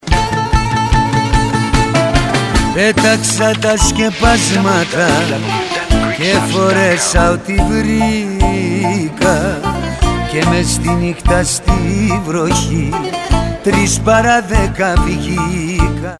modern Greek hits
LIVE renditions